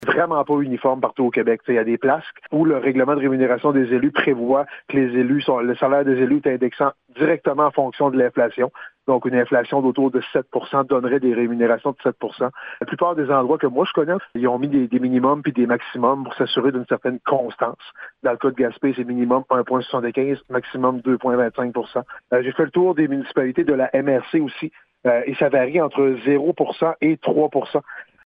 Le maire de Gaspé, Daniel Côté, explique que chaque ville a son propre règlement concernant la rémunération de leurs élus :